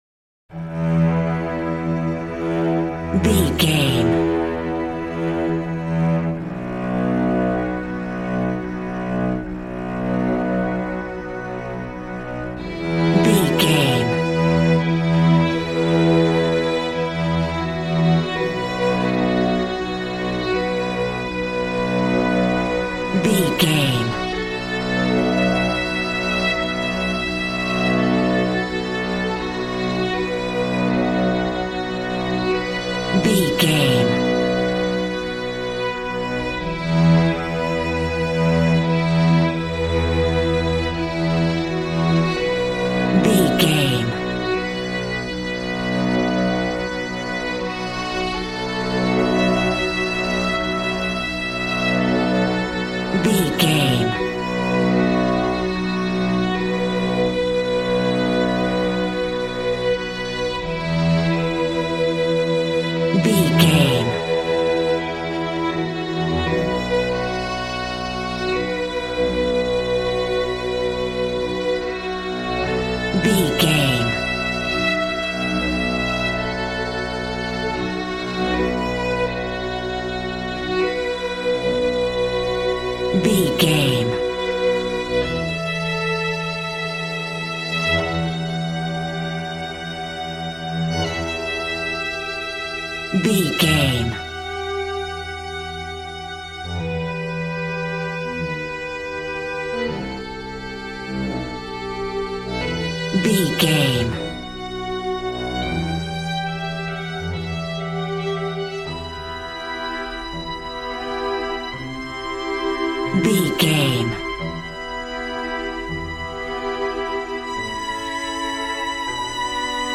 Ionian/Major
regal
brass